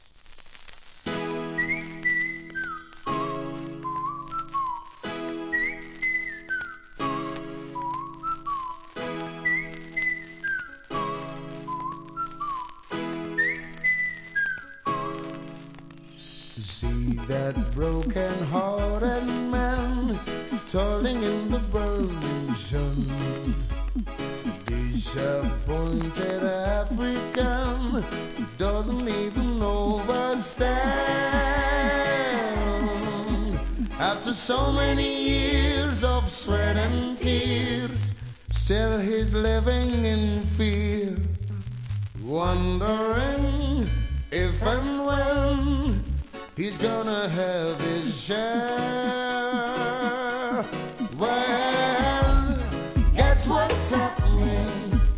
REGGAE
イントロの口笛も印象的な1996年人気曲!!